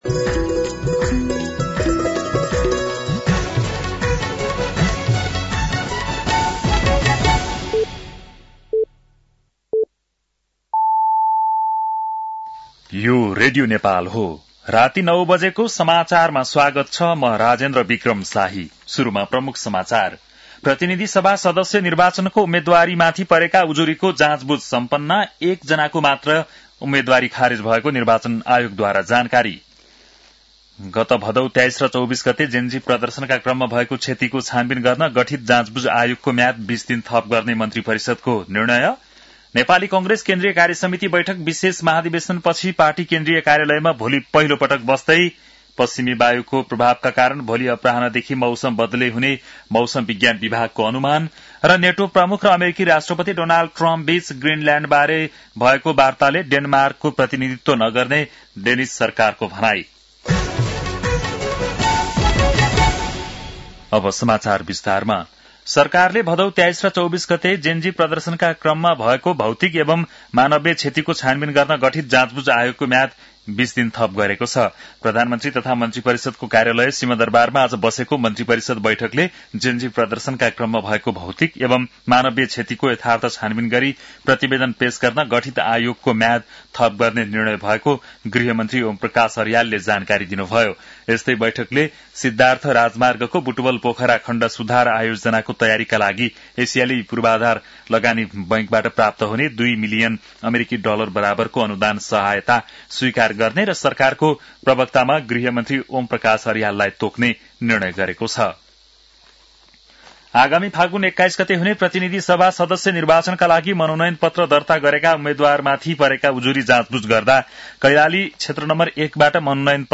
बेलुकी ९ बजेको नेपाली समाचार : ८ माघ , २०८२
9-PM-Nepali-NEWS-1-1.mp3